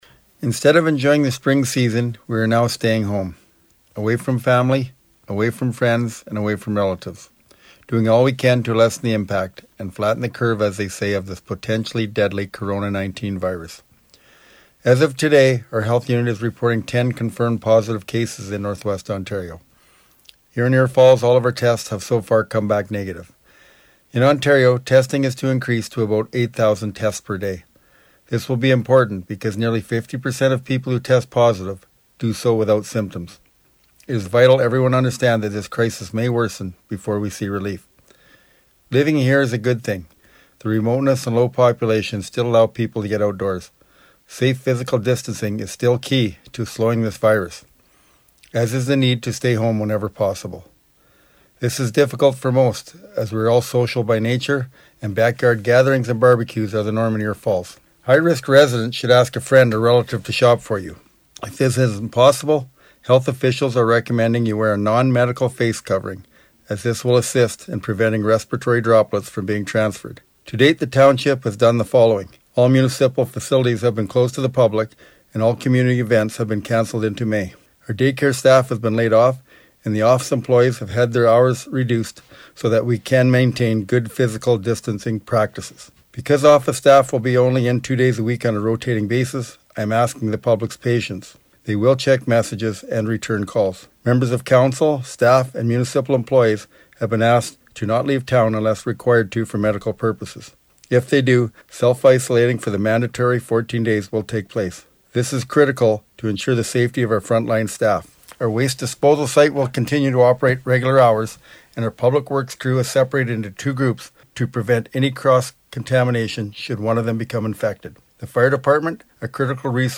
Kevin Kahoot provided a municipal update on the CKDR Morning Show today. (Tuesday)